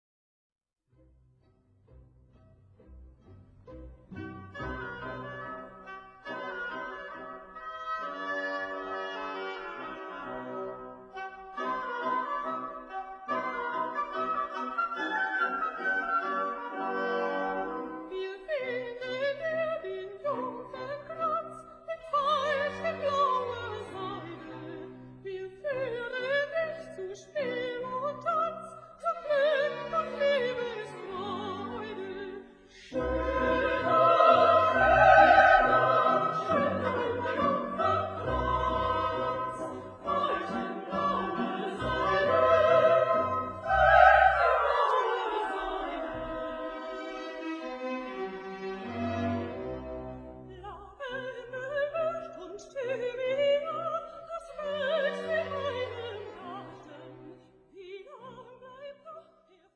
Der Chor der Brautjungfern